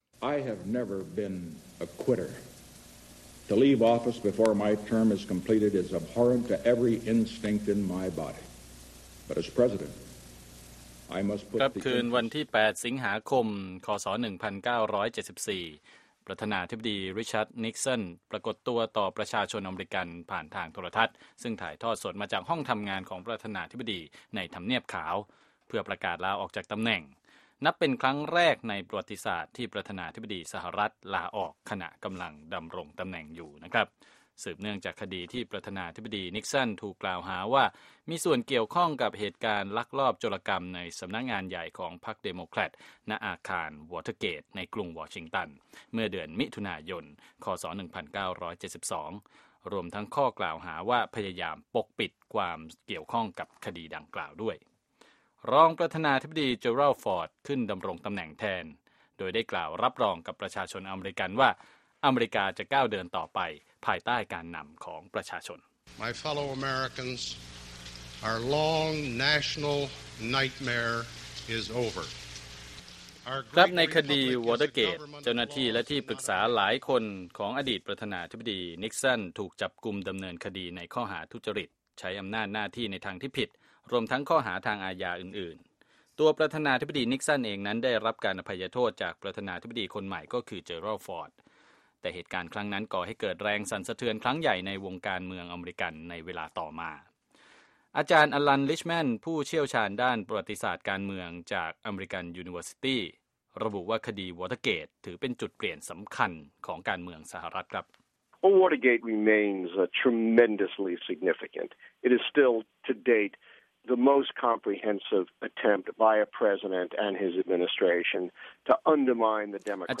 by วอยซ์ ออฟ อเมริกา